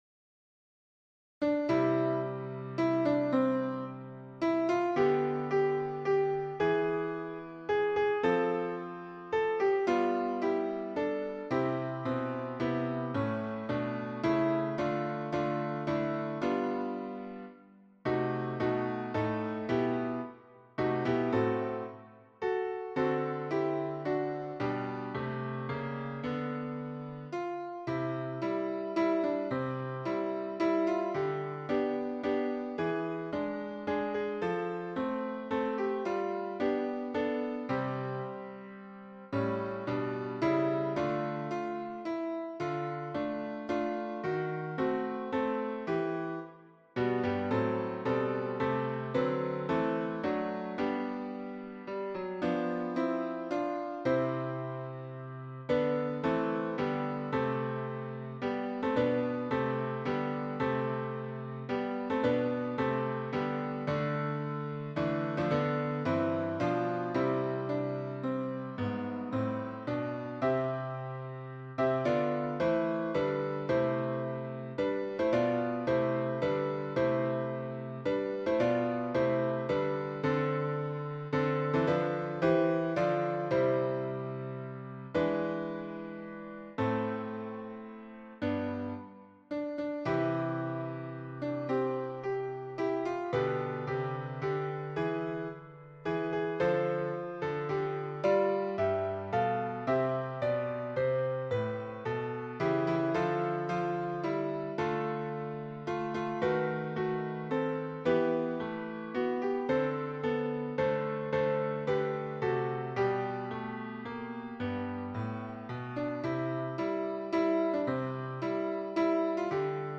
MP3 version piano
Tutti : SATB